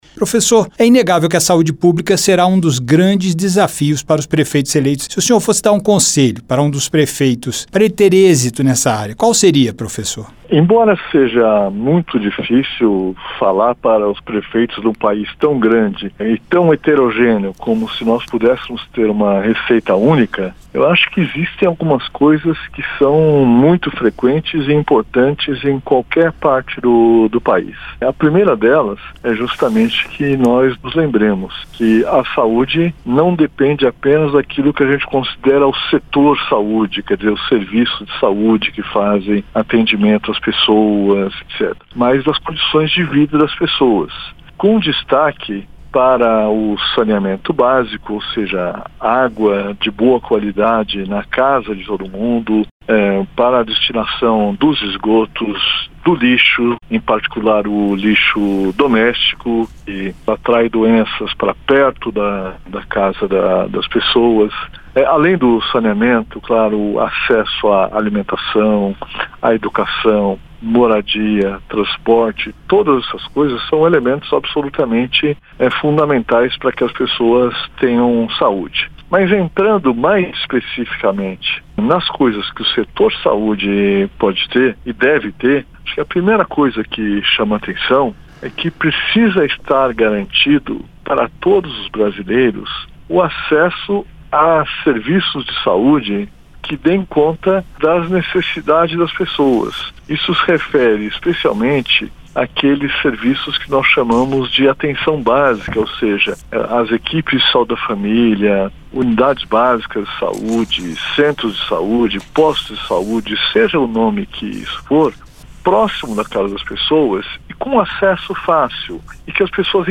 * Entrevista originalmente veiculada em 25/09/2024.